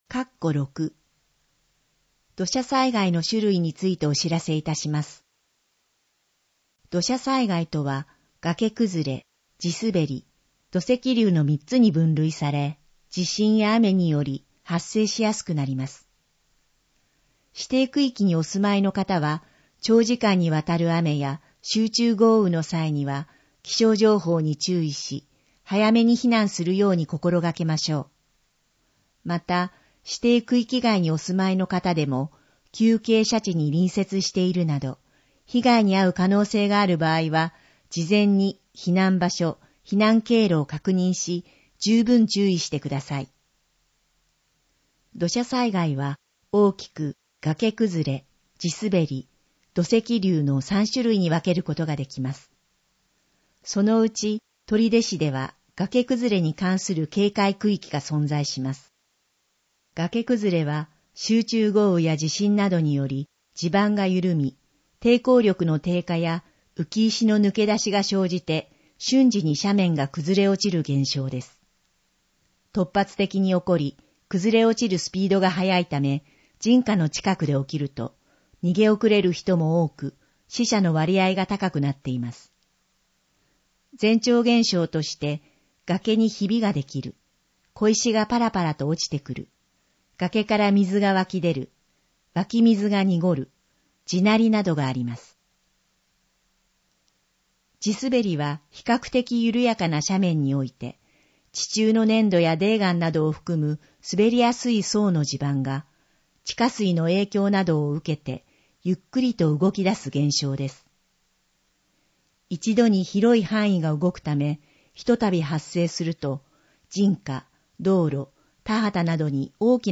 総合防災マップの内容を音声で聞くことが出来ます。音声データは市内のボランティア団体、取手朗読奉仕会「ぶんぶん」の皆さんのご協力により作成しています。